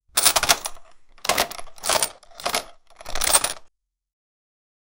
Money register sound
money-register-sound-5ufyg3hv.wav